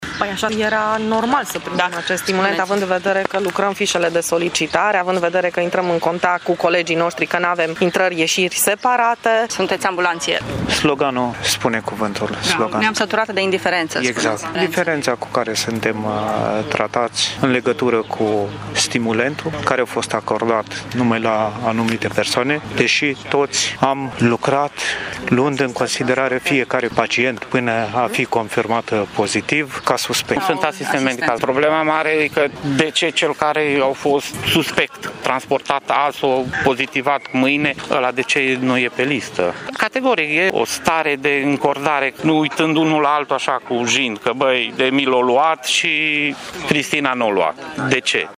Personalul TESA și cel auxiliar reclamă faptul că a fost lăsat deoparte la acordarea stimulentului de risc, fapt ce a divizat angajaţii Ambulanţei, spun sindicaliștii: